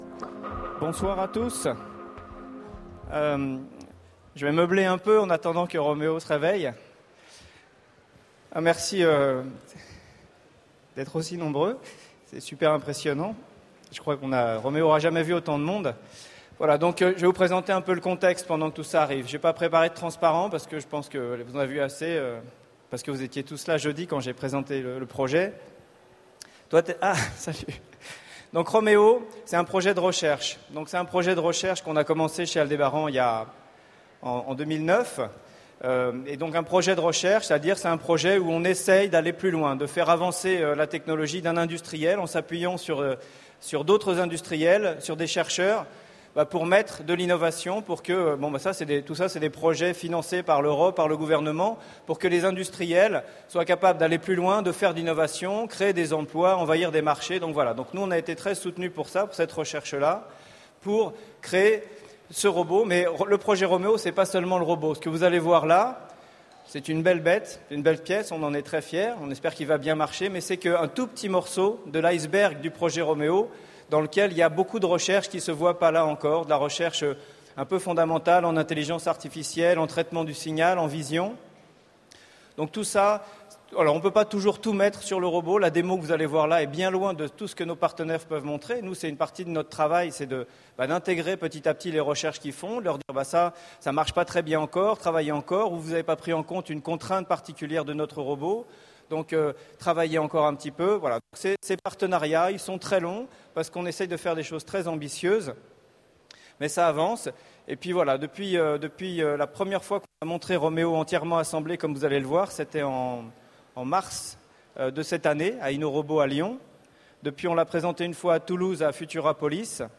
Mots-clés Robot Conférence Partager cet article